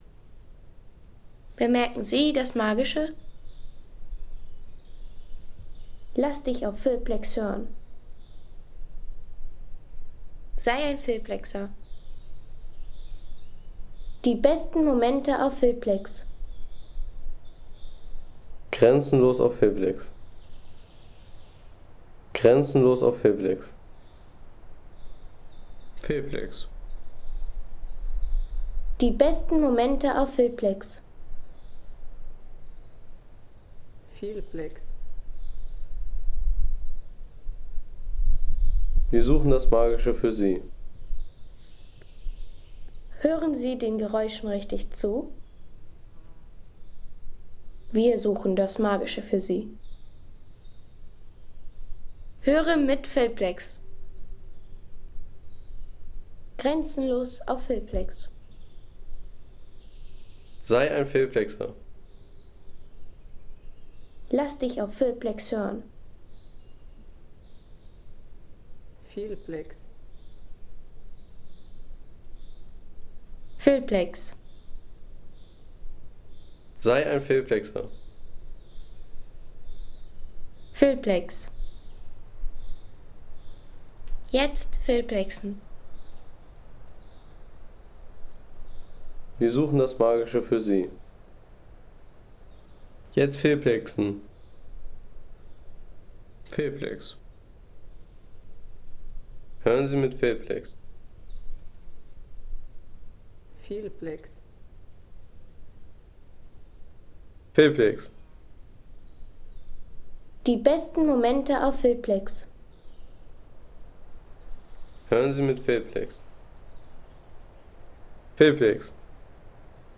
Der Wald am Brocken
Der Wald am Brocken besitzt in einigen Abschnitten eine mystische und ... 4,00 € Inkl. 19% MwSt.